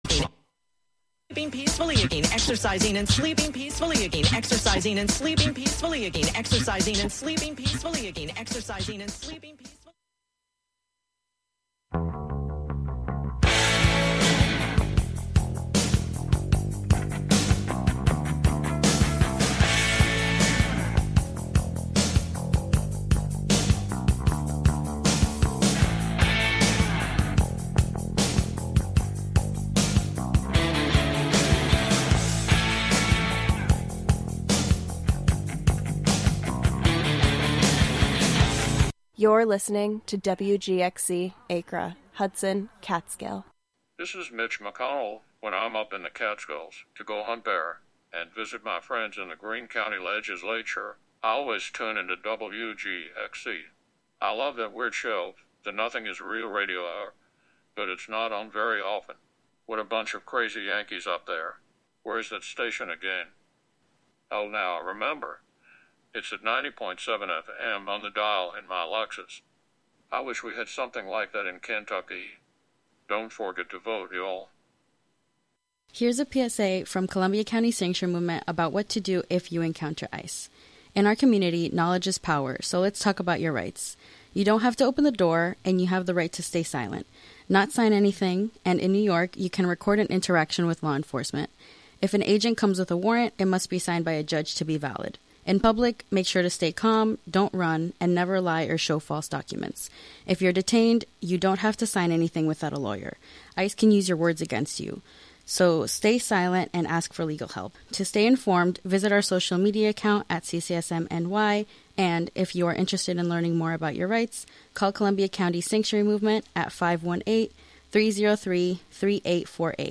Inspired by the Hoffmann Bird Club members’ impressive imitation of owls during the owl prowl, this month’s episode features recordings of Inuit women imitating geese, Tuva people imitating owls and wolves, and the Kayabí, an indigenous group from Mato Grosso, Brazil, imitating birds.
Every episode features an interview with local bird people, plus a freeform mix of sound made by birds and humans inspired by birds.